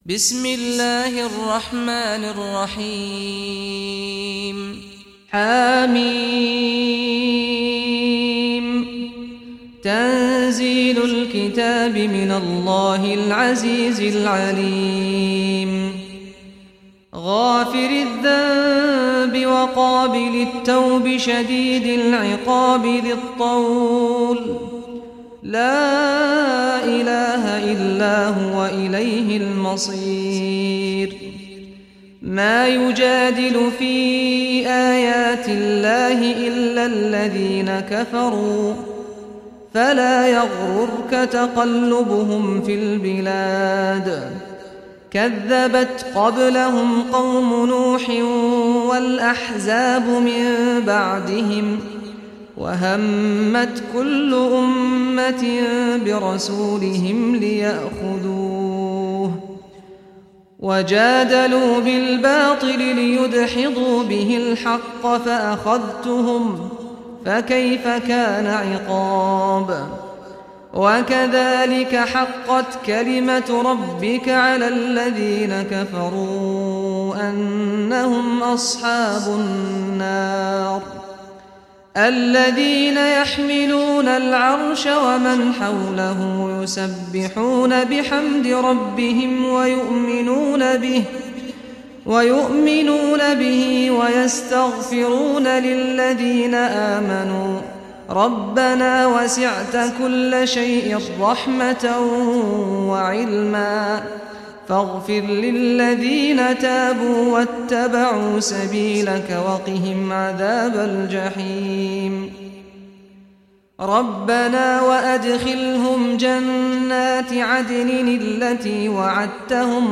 Surah Ghafir Recitation by Sheikh Saad al Ghamdi
Surah Ghafir, listen or play online mp3 tilawat / recitation in Arabic in the beautiful voice of Sheikh Saad al Ghamdi.